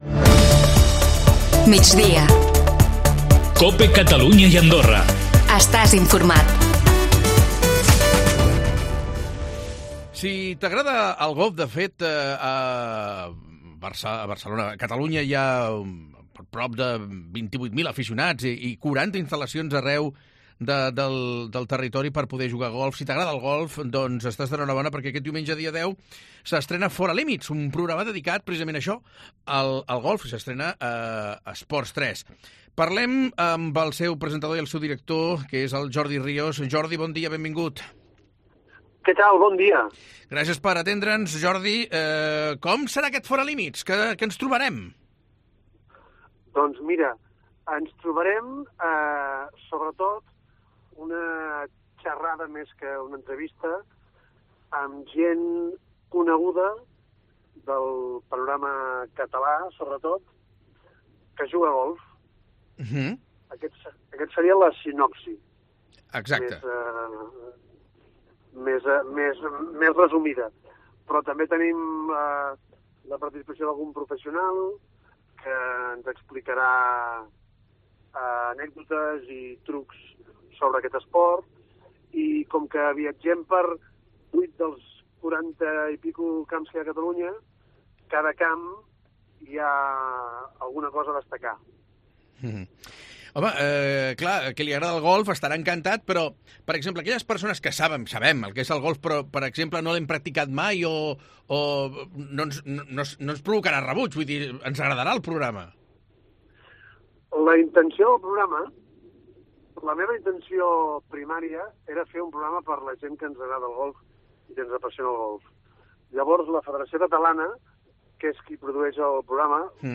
Jordi Rios serà el presentador i director del nou programa per a televisió, produït per la Federació Catalana de Golf per a la Corporació Catalana de Mitjans Audiovisuals, i amb ell hem parlat a MIgdia a COPE Catalunya. (àudio)